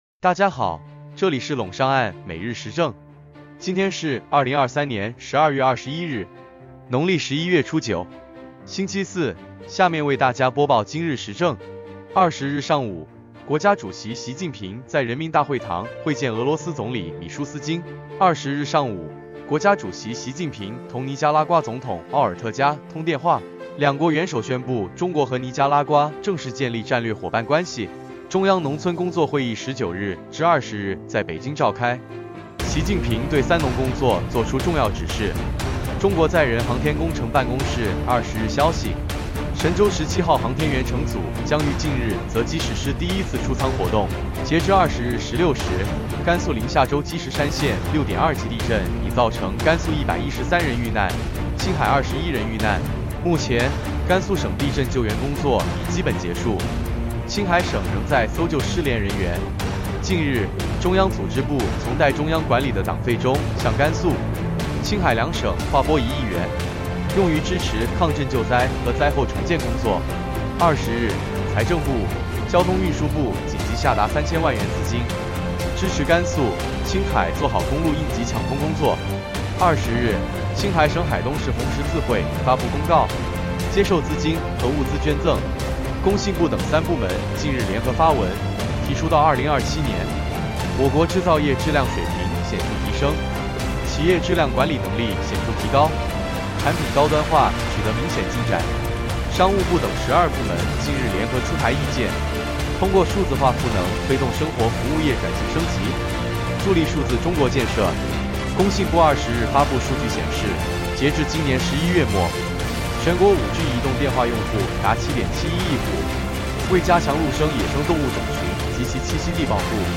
▼今日时政语音版▼